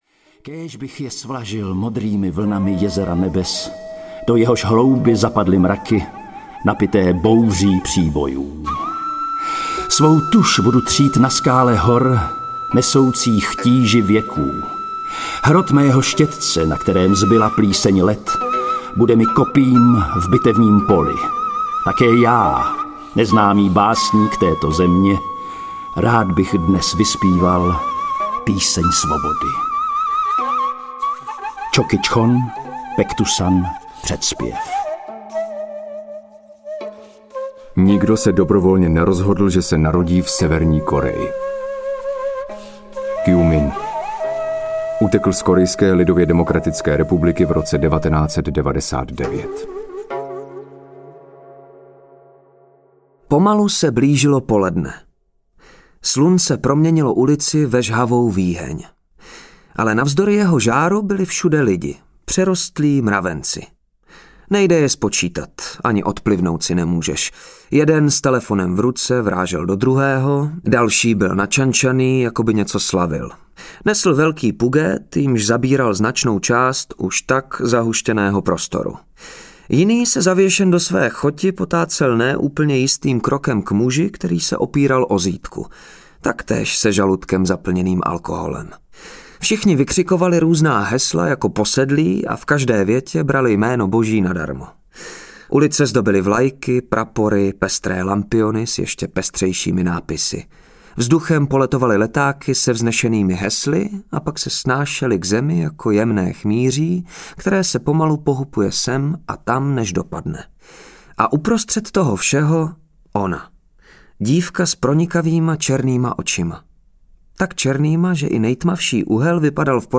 Severka audiokniha
Ukázka z knihy